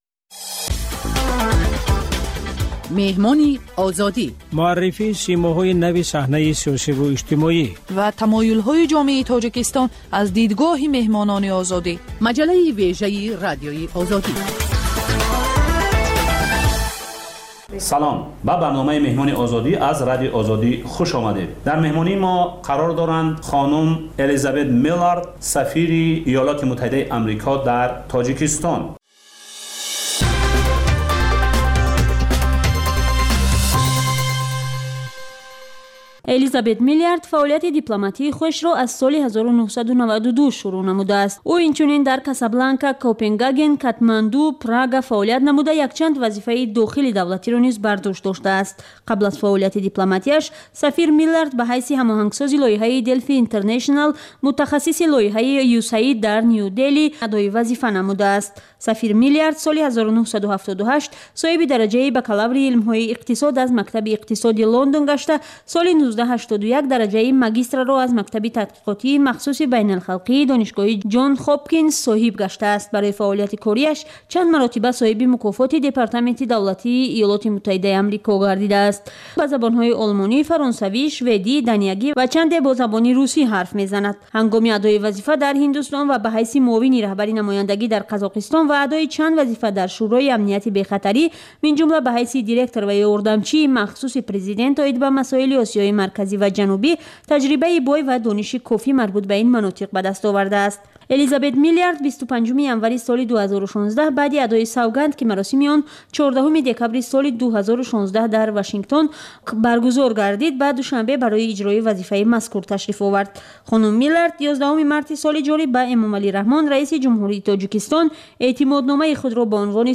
Гуфтугӯи ошкоро бо шахсони саршинос ва мӯътабари Тоҷикистон, сиёсатмадорону ҷомеашиносон, ҳунармандону фарҳангиён